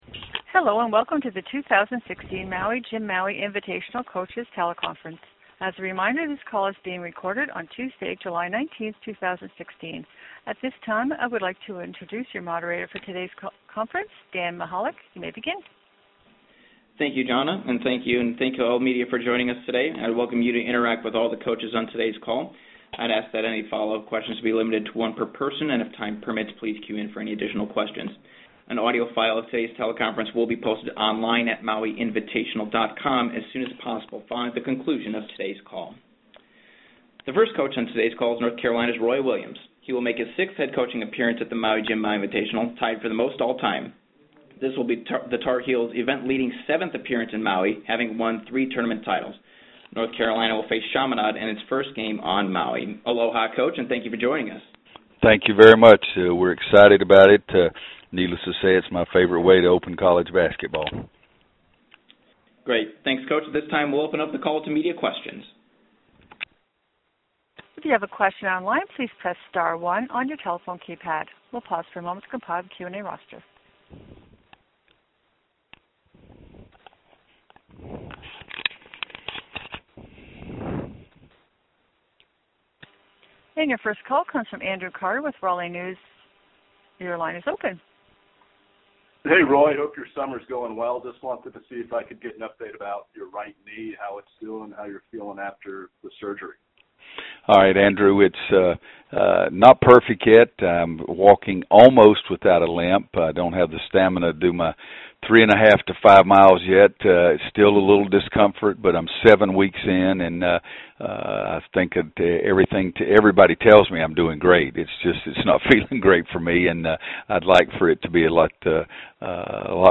Maui Jim Maui Invitational Coaches Teleconference Audio